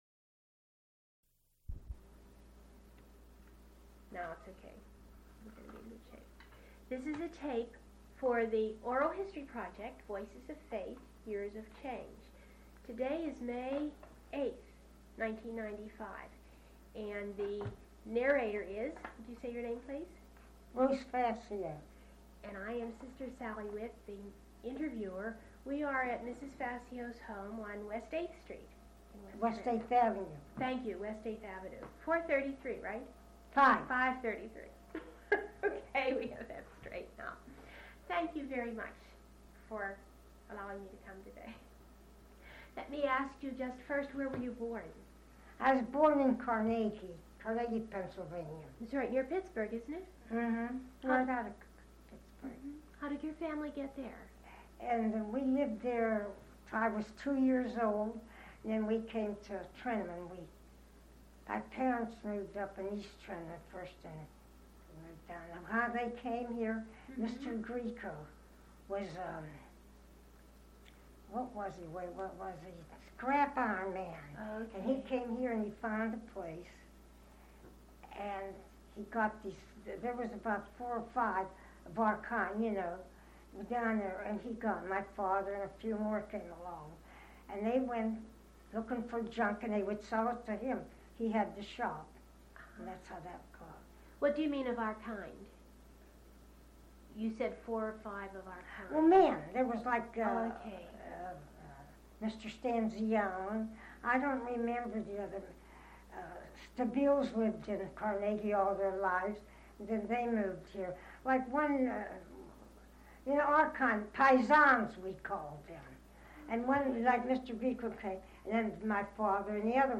oral histories (literary genre)
cassette tape